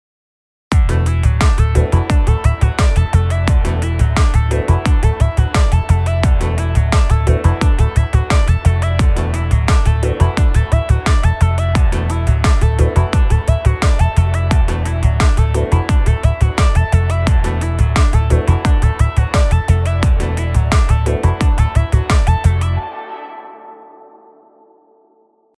Требуется помощь с басом